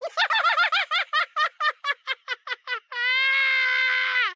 *大笑*